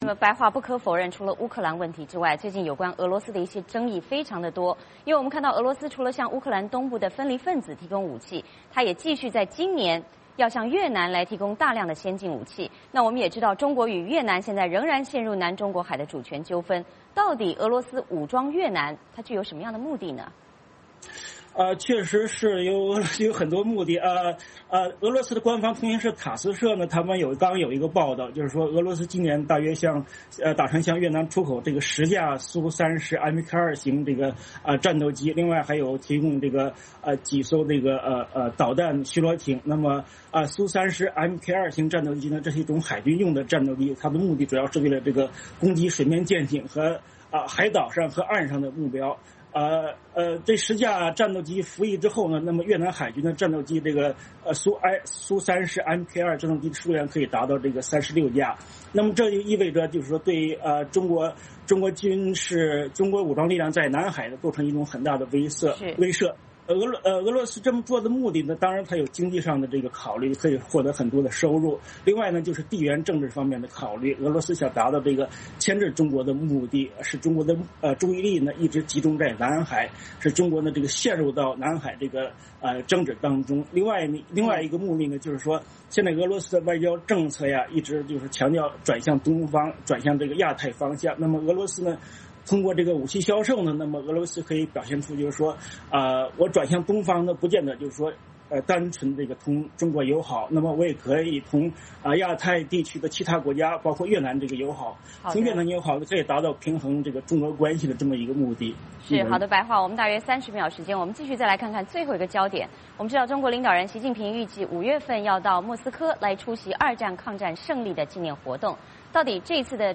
VOA连线：俄罗斯大规模武装越南的目的